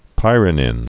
(pīrə-nĭn) also py·ro·nine (-nēn)